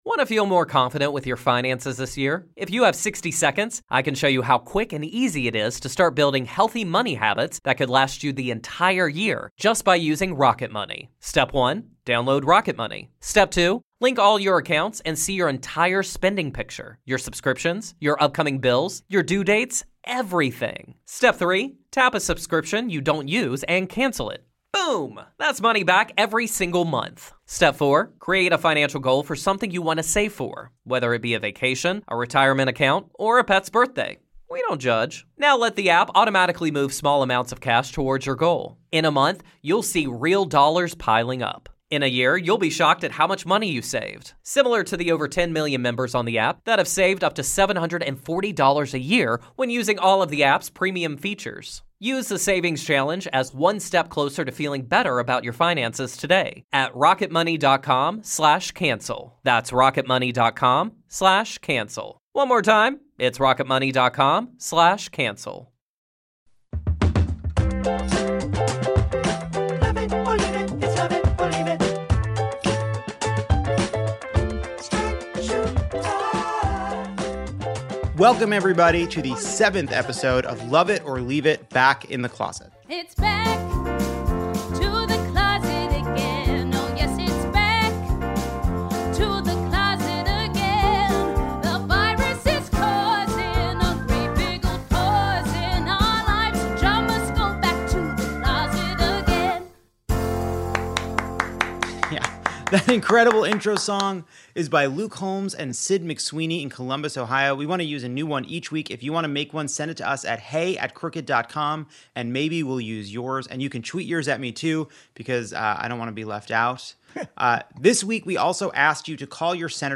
John Hodgman judges the monologue. Ronan Farrow and I face tough questions from Akilah Hughes. Katie Porter joins for an update from Congress. And we hear this week’s highs (and a few rants) from listeners.